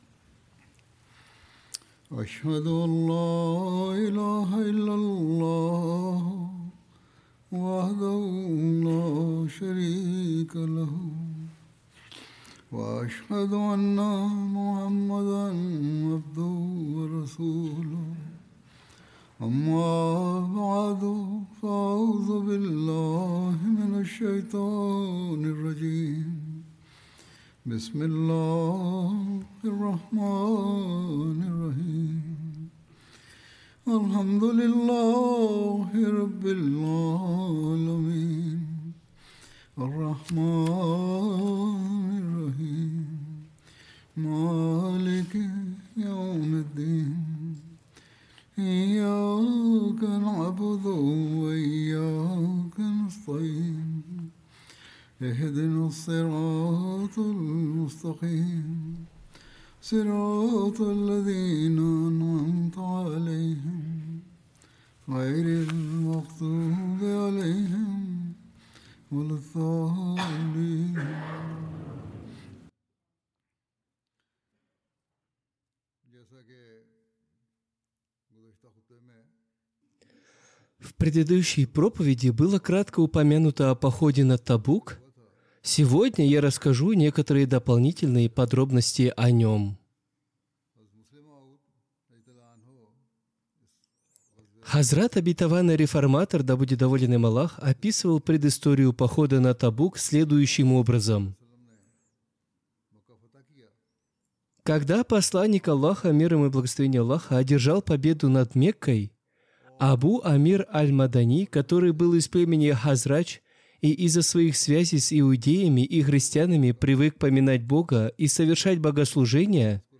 Russian Translation of Friday Sermon delivered by Khalifatul Masih